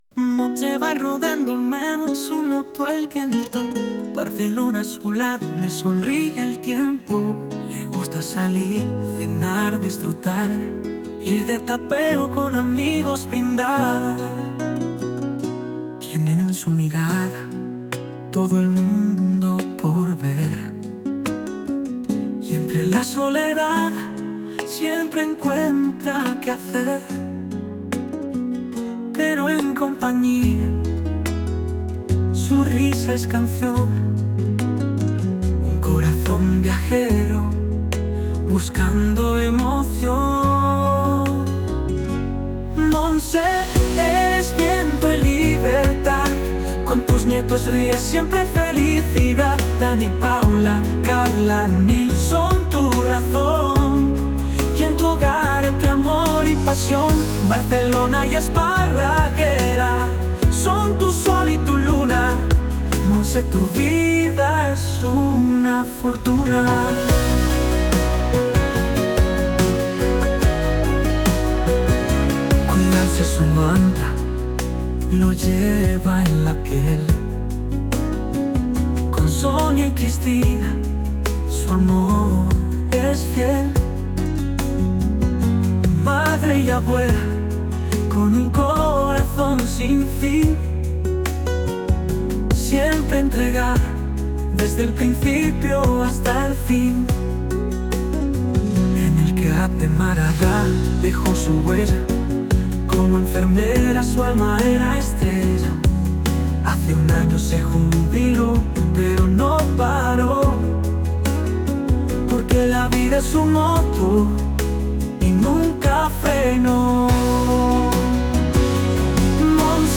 Cancion con inteligencia artificial